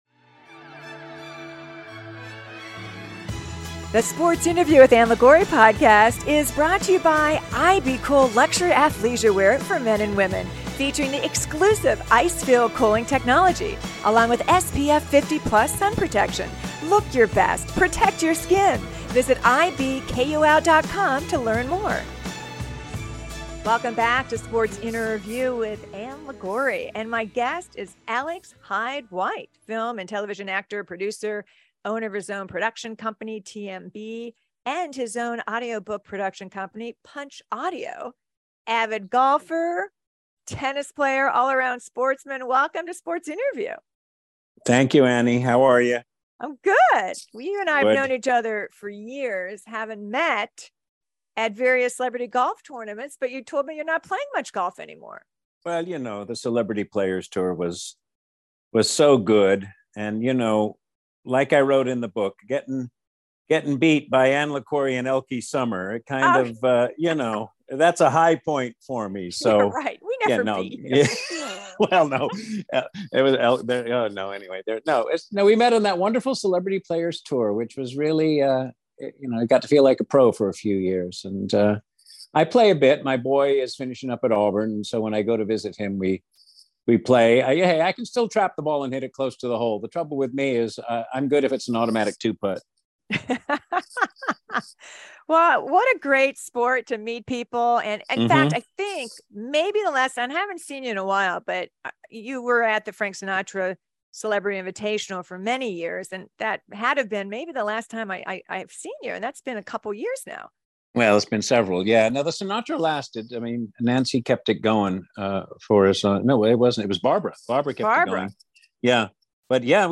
Sports Innerview 11/12/2022 - Alex Hyde-White Interview